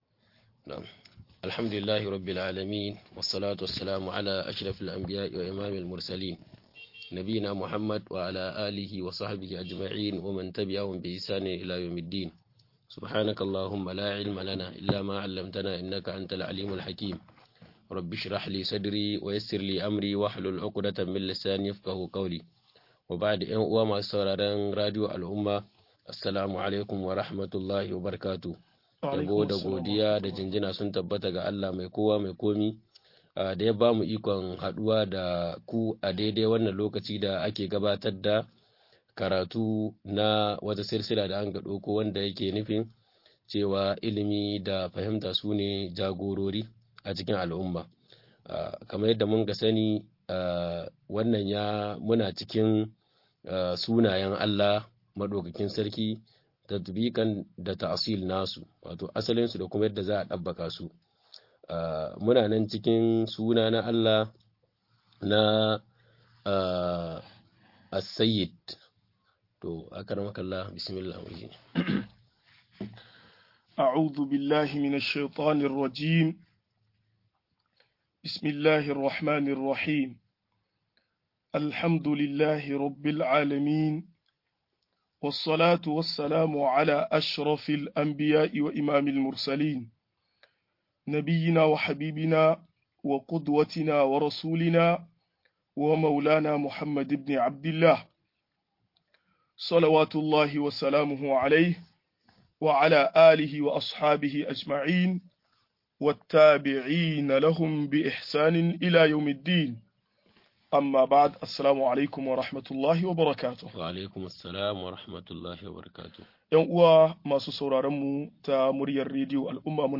Sunayen Allah da siffofin sa-09 - MUHADARA